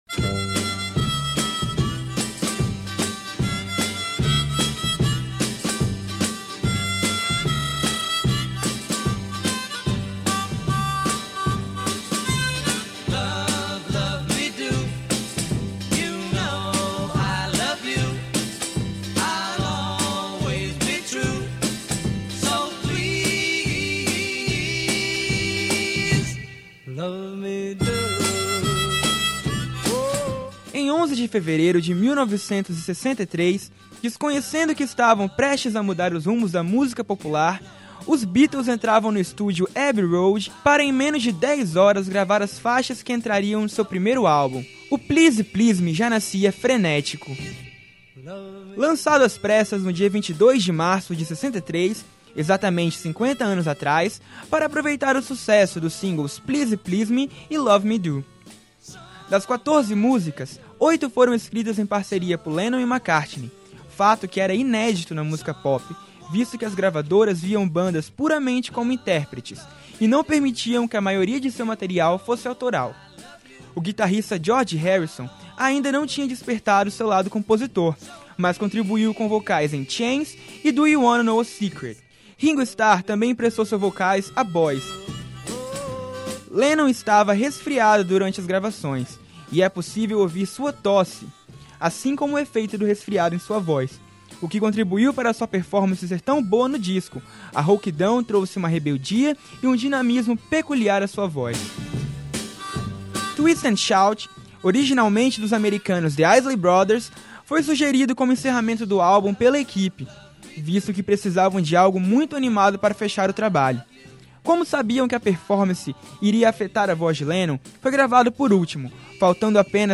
Matéria Beatles Download : Matéria Beatles